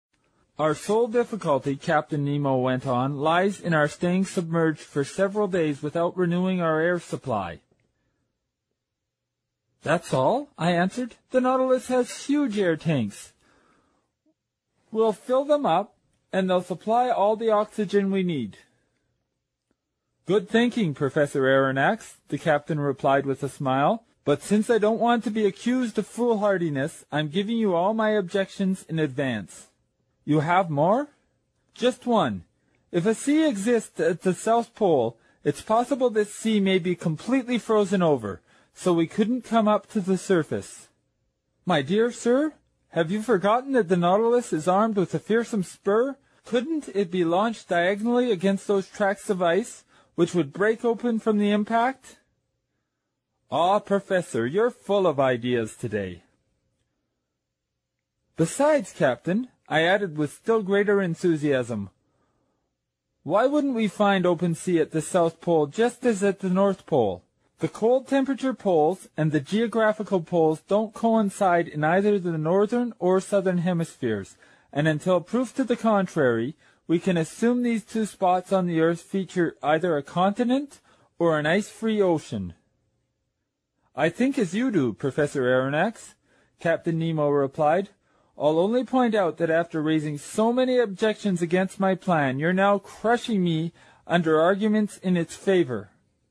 英语听书《海底两万里》第437期 第26章 大头鲸和长须鲸(18) 听力文件下载—在线英语听力室
在线英语听力室英语听书《海底两万里》第437期 第26章 大头鲸和长须鲸(18)的听力文件下载,《海底两万里》中英双语有声读物附MP3下载